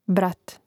brȁt brat